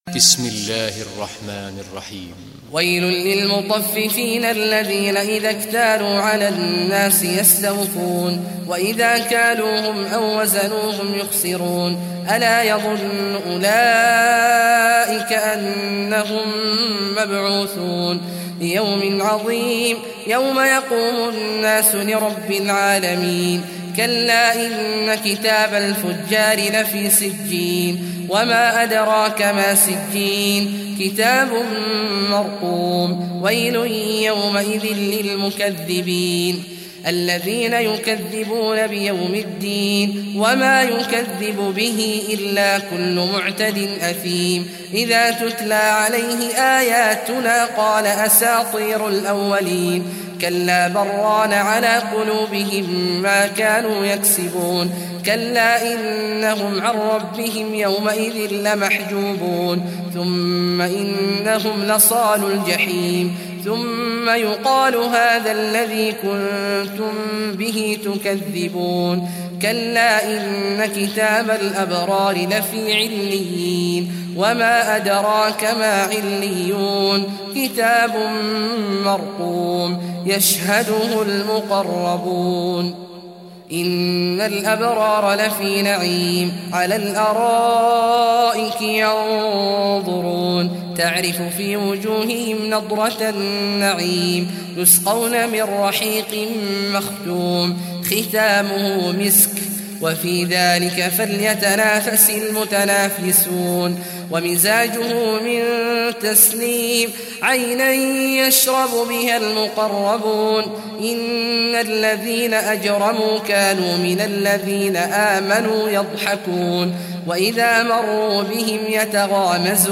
Surah Mutaffifin Recitation by Sheikh Awad Juhany
Surah Mutaffifin, listen or play online mp3 tilawat / recitation in Arabic in the beautiful voice of Sheikh Abdullah Awad al Juhany.